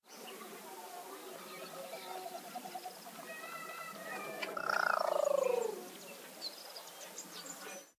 birdsounds